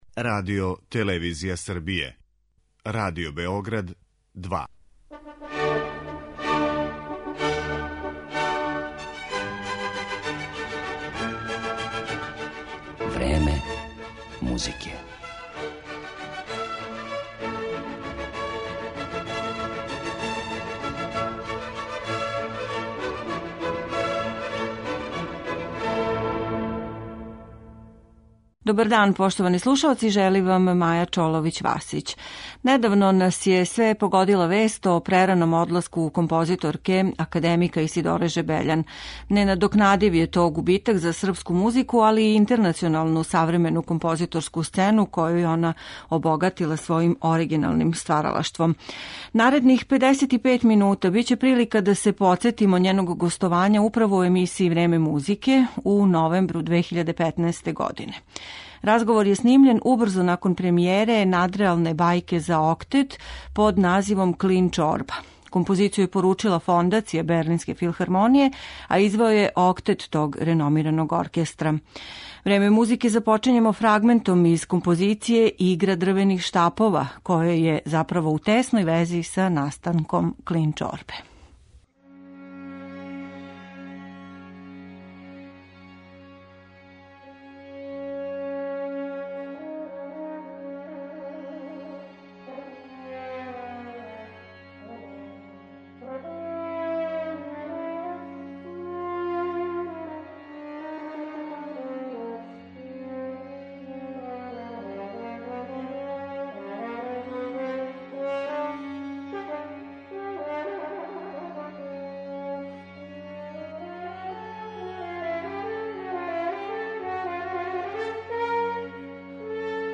Разговор је употпуњен избором дела из њеног оригиналног и разноврсног композиторског опуса.